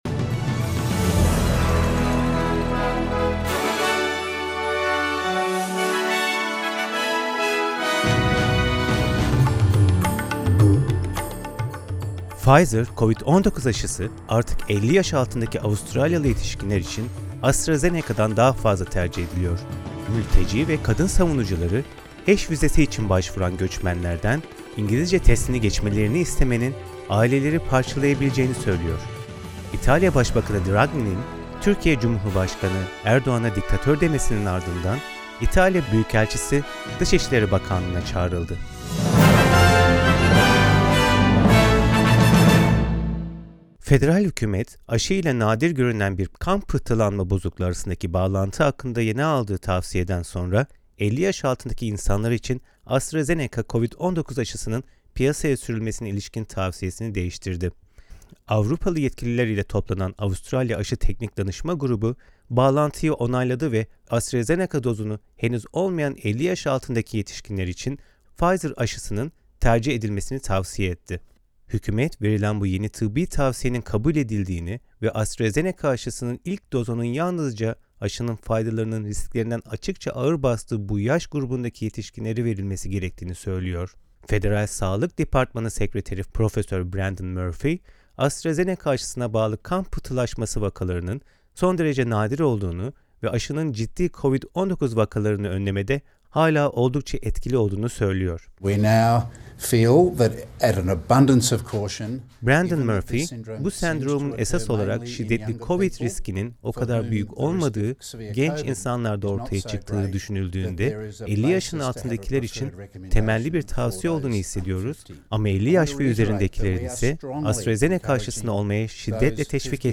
SBS Türkçe’den Avustralya, Türkiye ve dünyadan haberler.